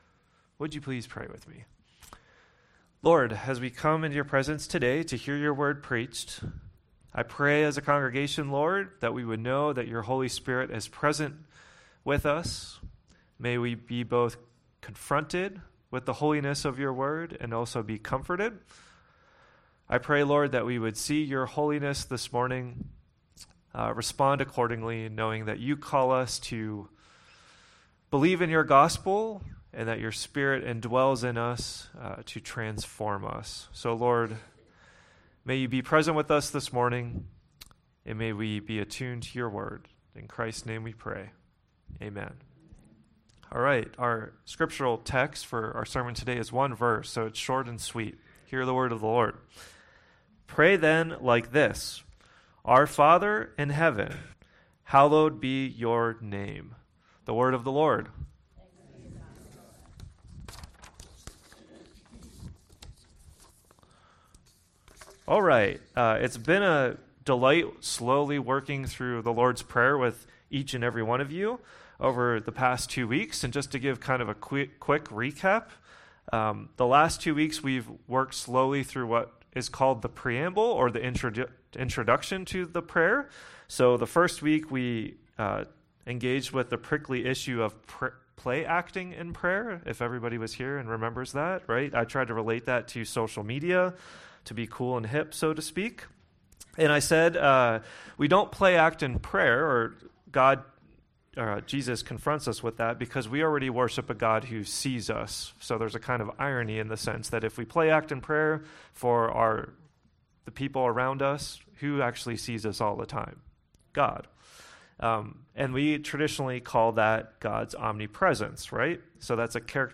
Sermon text: Matthew 6:9